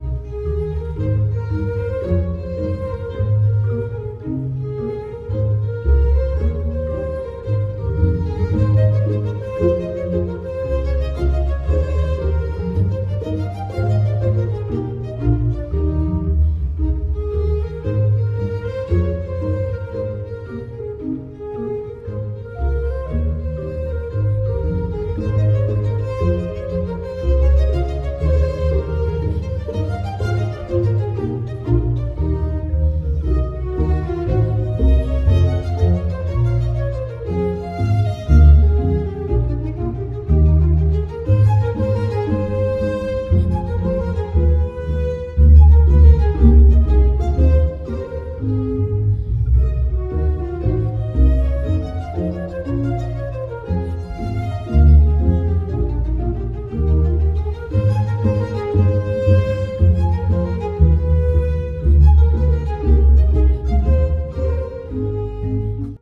BACH, cantate Bwv 30, aria alto - BRETON (Jules), glaneuse fatiguee.mp3